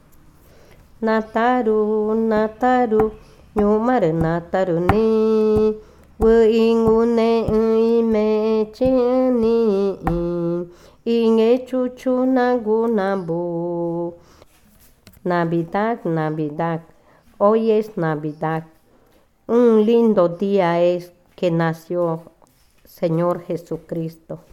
Canción infantil 20.
Cushillococha